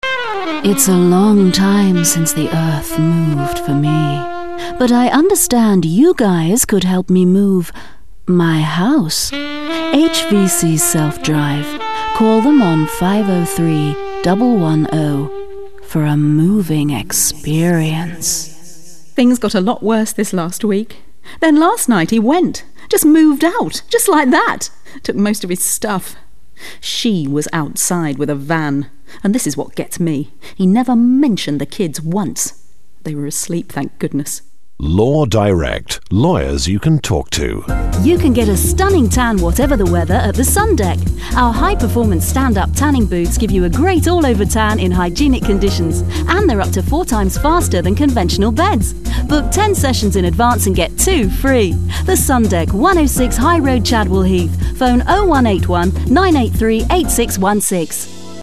Female Voice Over Artists
Notes: American, fun, upbeat, chatty Notes: